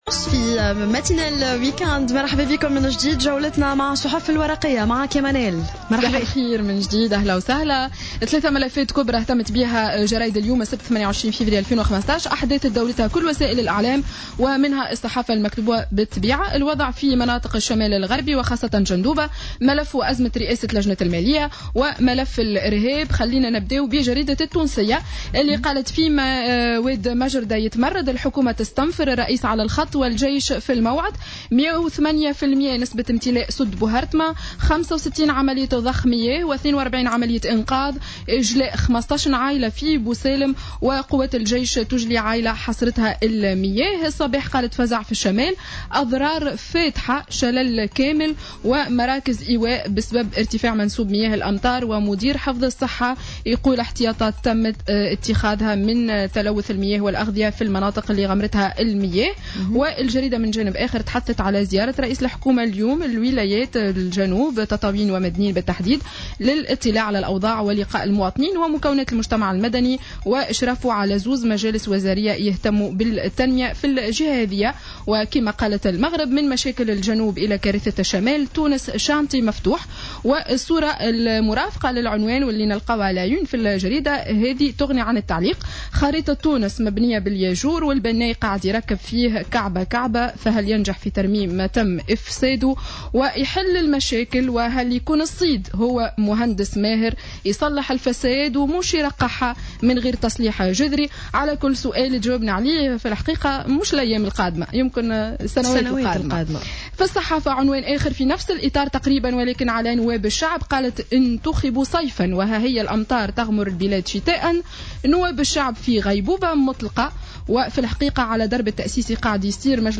Revue de presse du 28-02-15